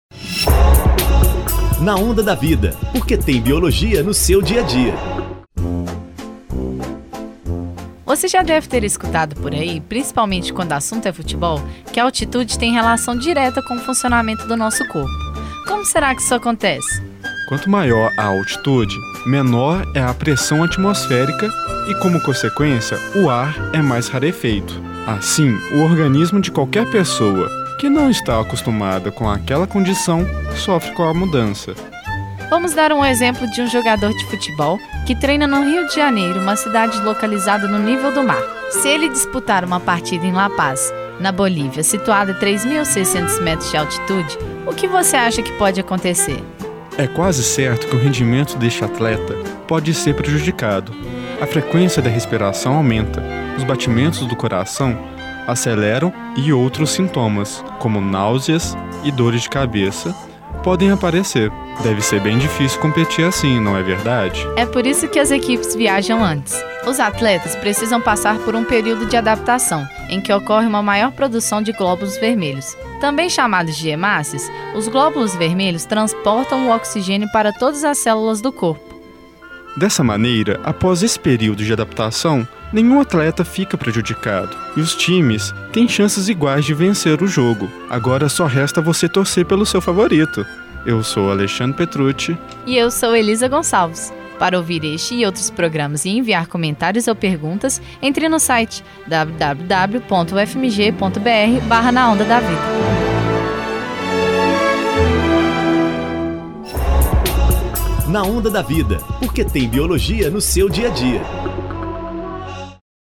Na Onda da Vida” é um programa de divulgação científica através do rádio